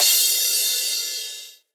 clashA.ogg